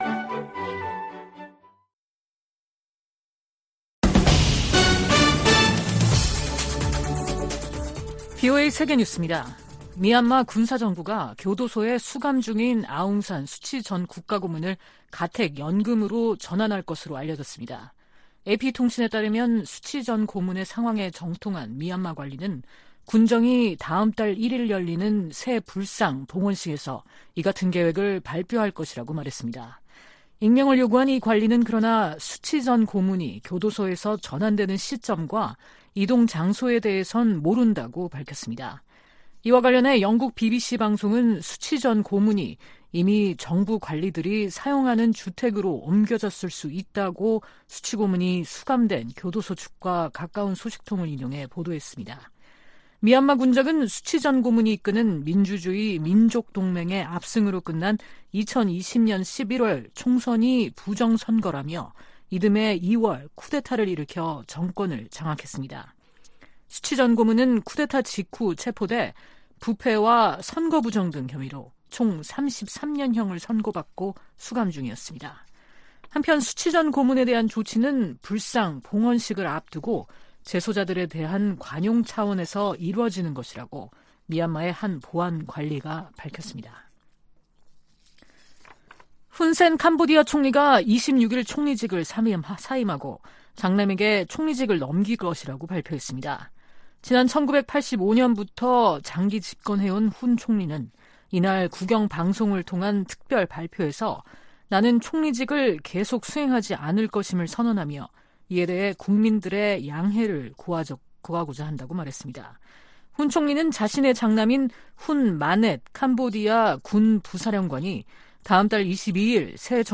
VOA 한국어 아침 뉴스 프로그램 '워싱턴 뉴스 광장' 2023년 7월 27일 방송입니다. 북한의 '전승절' 행사에 중국과 러시아 대표단이 참가하면서 북중러 3각 밀착이 선명해지는 것으로 분석되고 있습니다. 미 국무부는 중국과 러시아가 북한의 불법 활동을 자제하는 역할을 해야 한다고 강조했습니다. 미 국방부는 월북한 미군 병사와 관련해 아직 북한 측의 응답이 없다고 밝혔습니다.